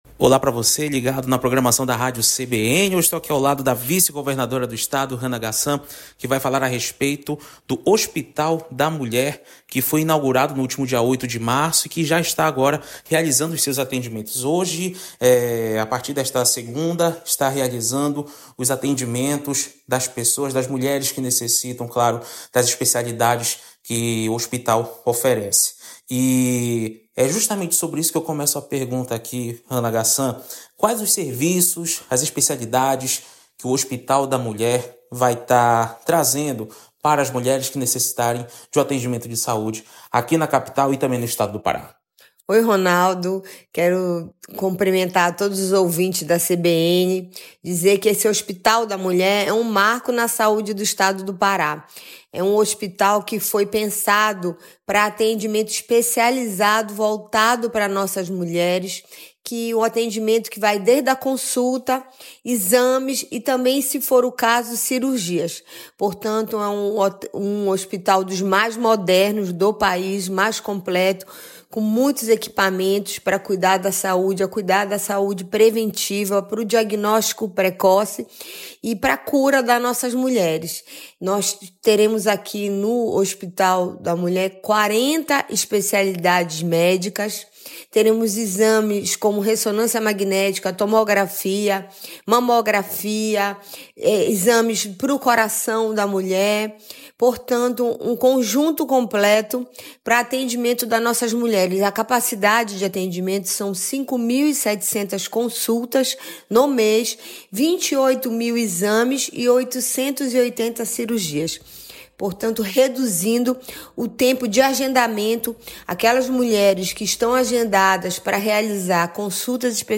O Hospital da Mulher do Pará atende mais de 20 especialidades, entre ginecologia, mastologia, cirurgia geral. Acompanhe entrevista da vice-governadora do Pará,  Hana Ghassan, para a Rádio CBN Amazônia Belém.